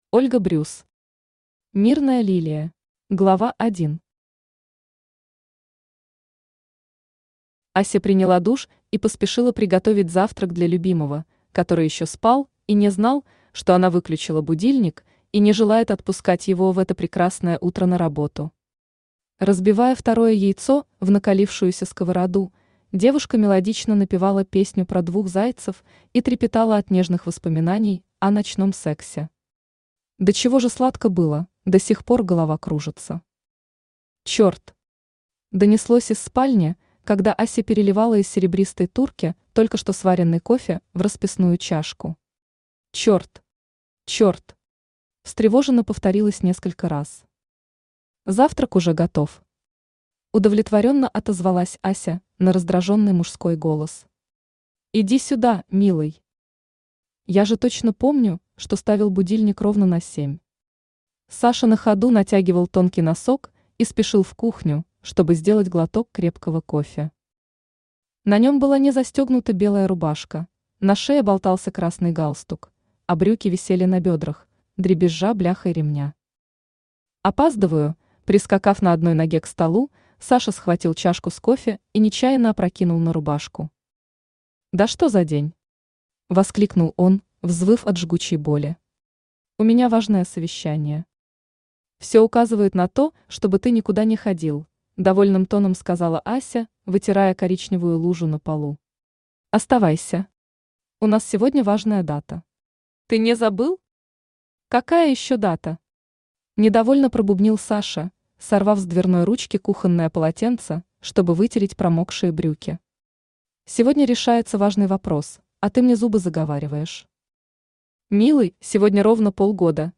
Аудиокнига Мирная лилия | Библиотека аудиокниг
Aудиокнига Мирная лилия Автор Ольга Брюс Читает аудиокнигу Авточтец ЛитРес.